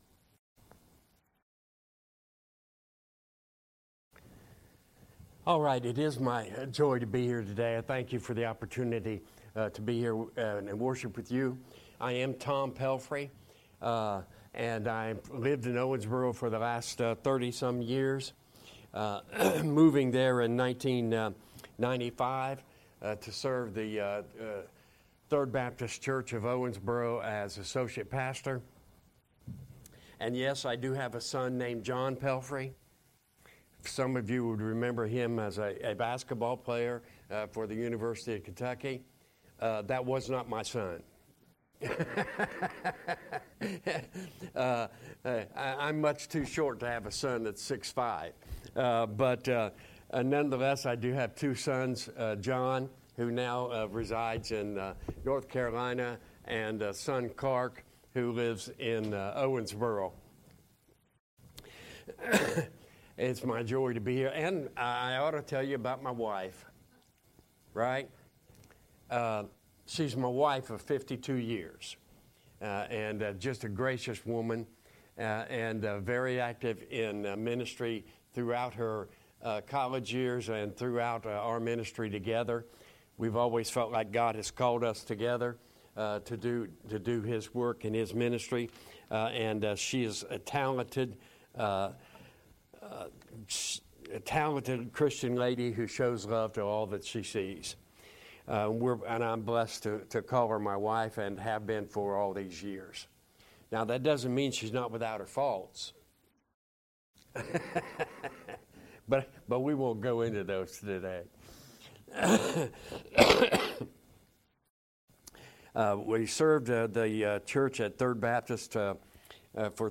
First Baptist Church Online Sermons